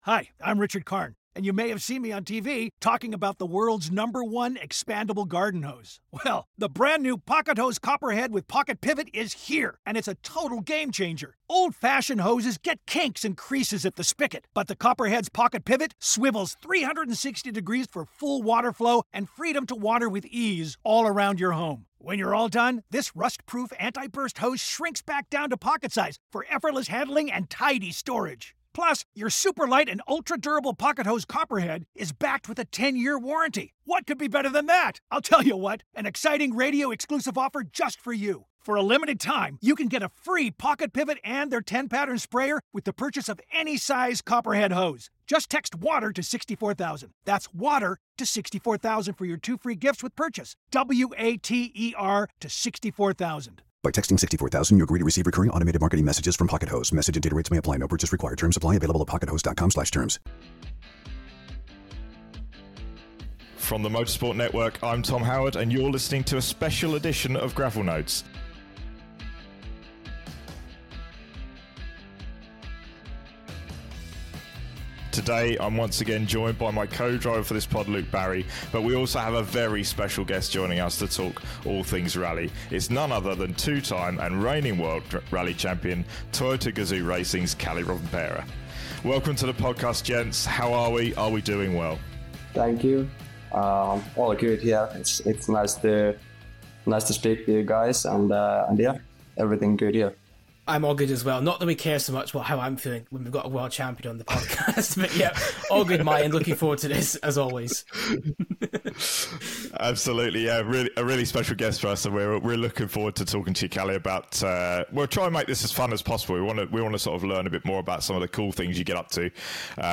Interview: Double World Champion Kalle Rovanperä